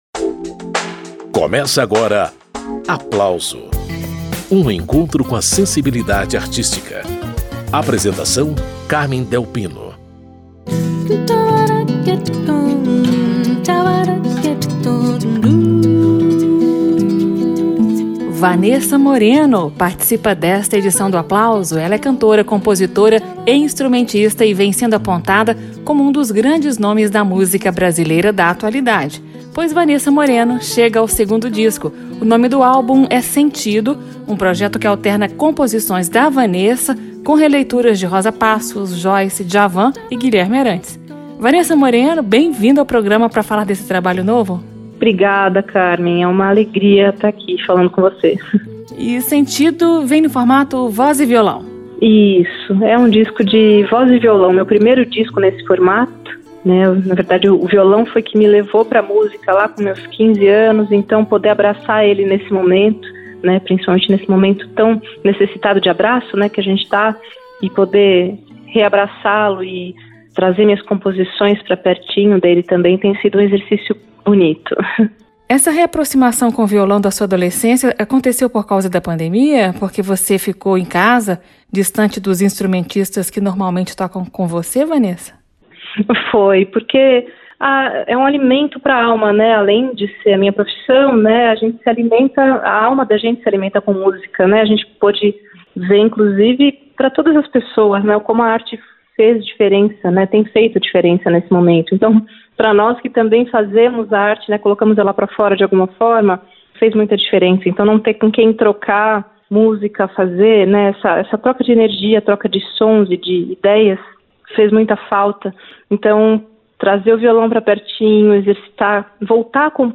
o violão.
retomou as pesquisas de voz e percussão vocal
dessa cantora, compositora e instrumentista paulista